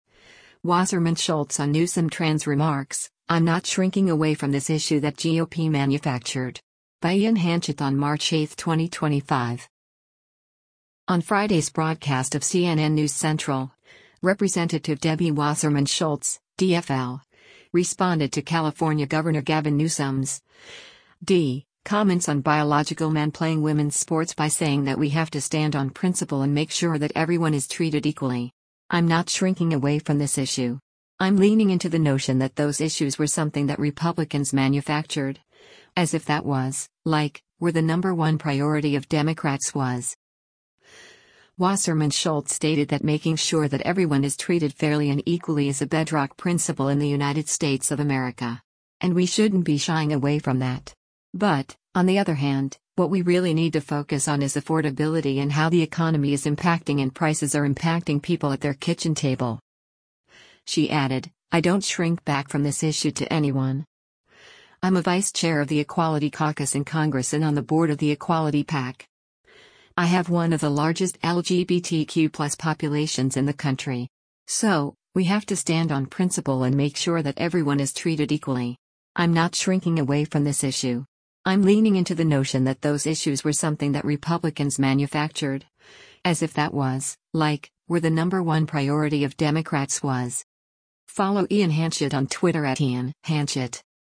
On Friday’s broadcast of “CNN News Central,” Rep. Debbie Wasserman Schultz (D-FL) responded to California Gov. Gavin Newsom’s (D) comments on biological men playing women’s sports by saying that “we have to stand on principle and make sure that everyone is treated equally.